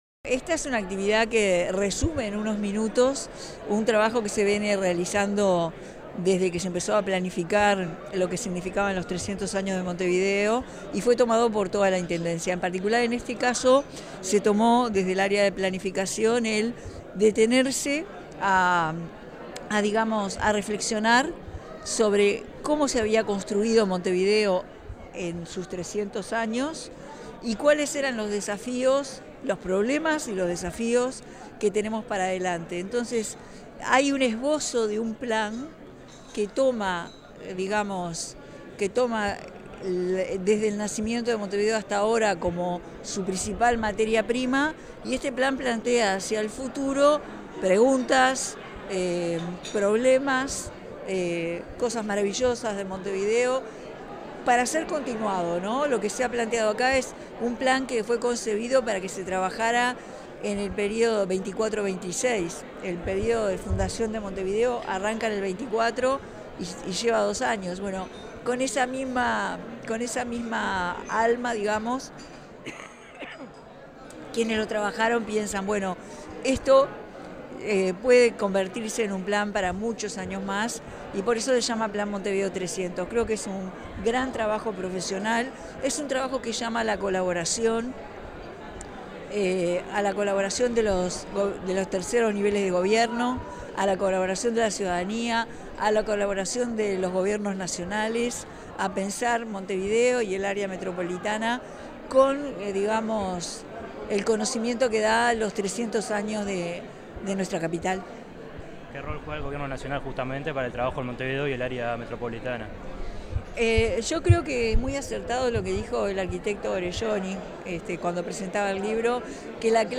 Declaraciones de la presidenta de la República en ejercicio, Carolina Cosse